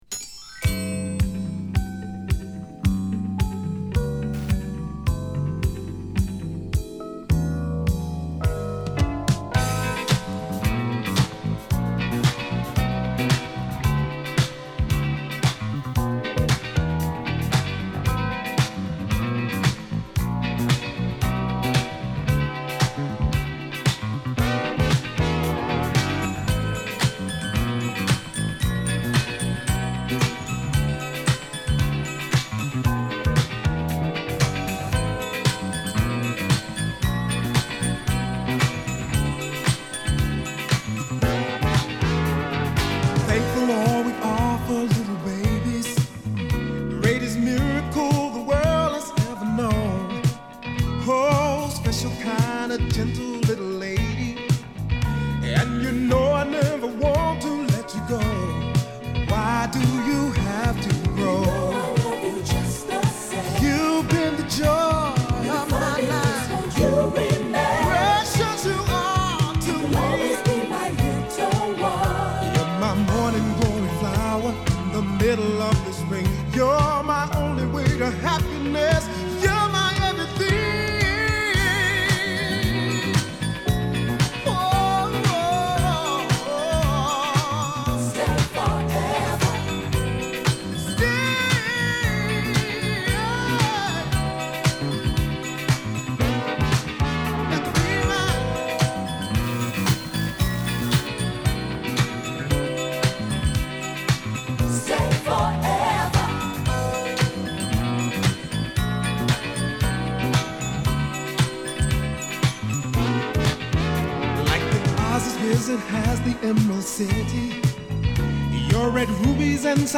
弾んだベースとクラップビートでの、コチラもアーバンな仕上がりのブギーソウル